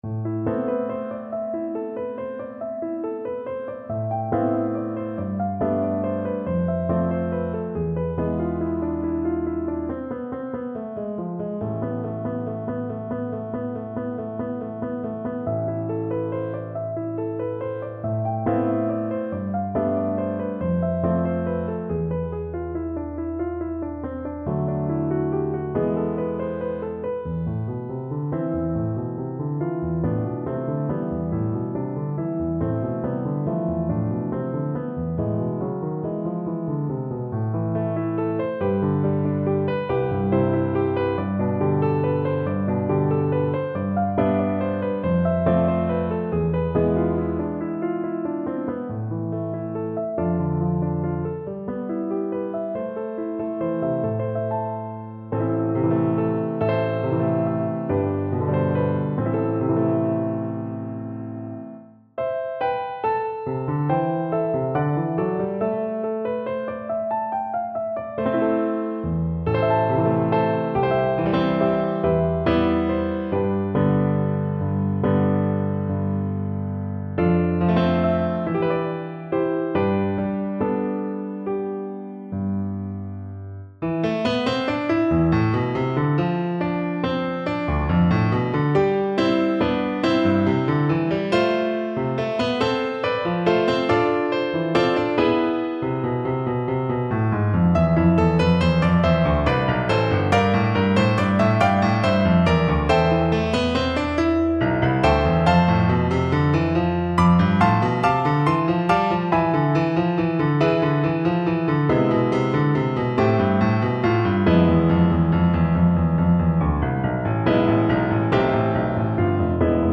A haunting arrangement of this famous British folk melody.
3/4 (View more 3/4 Music)
Fast, flowing =c.140
Traditional (View more Traditional Flute Music)